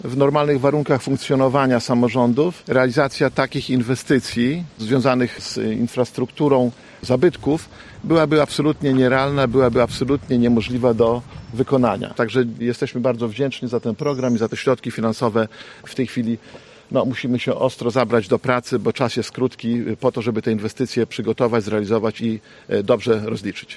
Dziś w Szczepankowie nastąpiło rozstrzygnięcie konkursów w ramach ochrony zabytków w powiecie łomżyńskim.
Starosta łomżyński, Lech Marek Szabłowski dodał, że realizacja tych zadań byłaby trudna bez pomocy państwa: